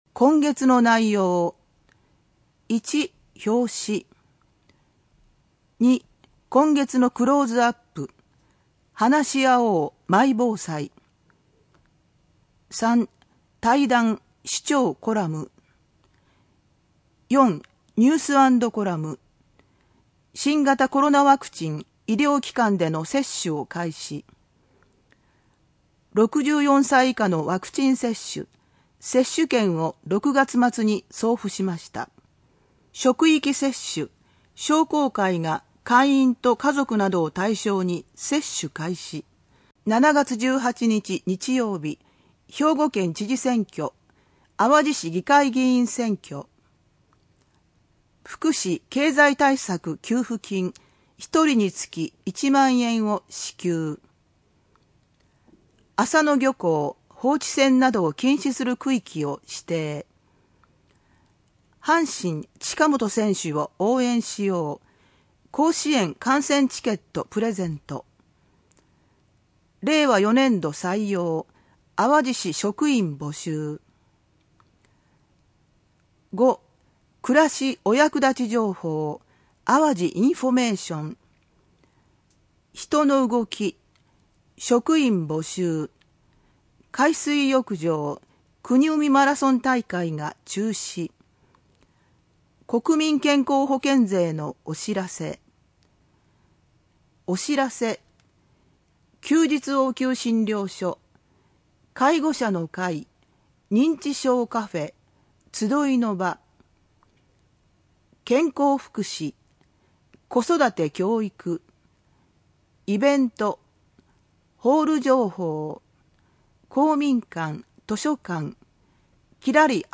朗読　東浦朗読ボランティアグループ・ひとみの会